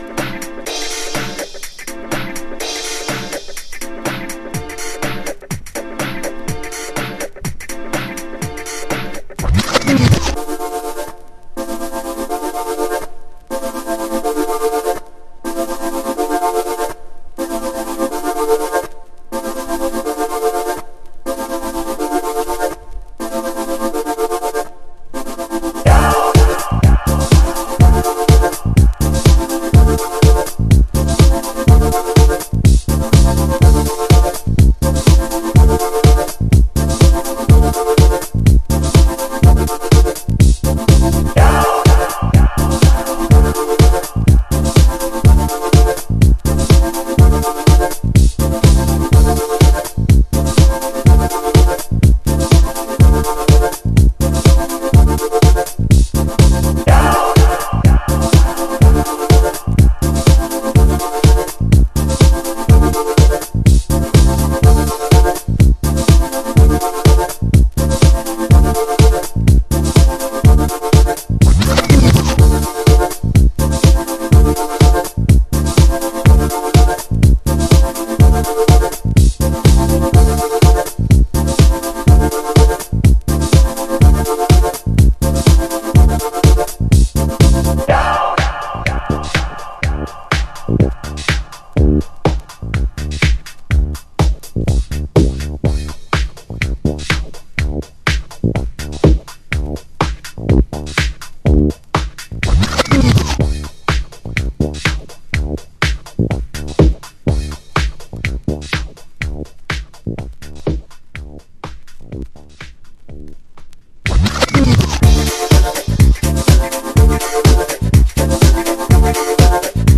荒めのフィルター使いやバックスピンなどを駆使したナイスブギーグルーヴ。